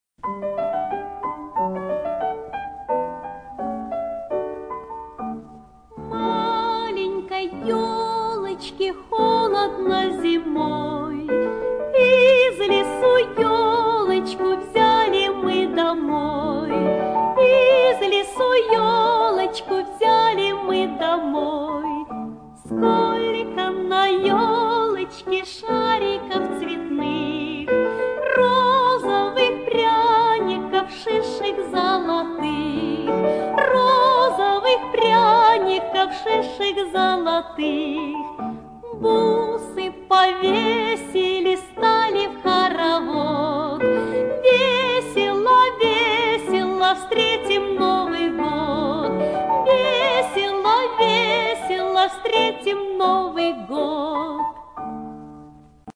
для детского сада